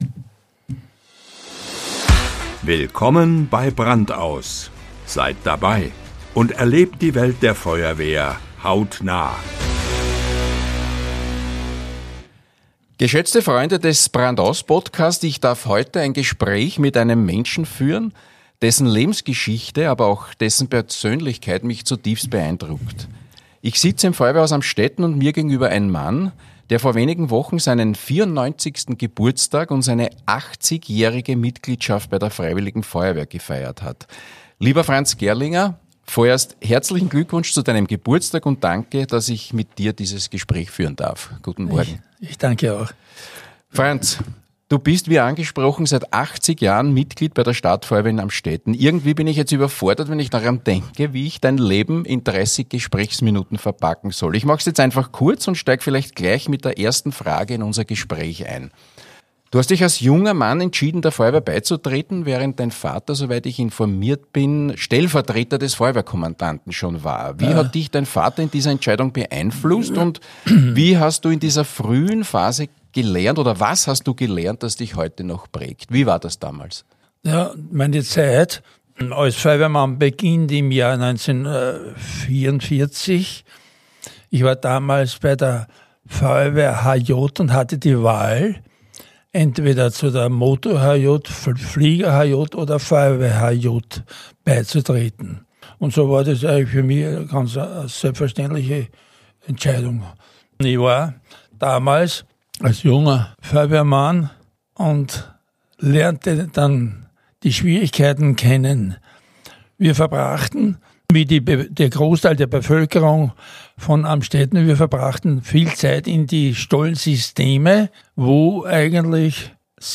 Das Podcast-Gespräch ist eine Liebeserklärung des Jubilars an die freiwillige Feuerwehr, der er bis zum letzten Atemzug treu bleiben wird.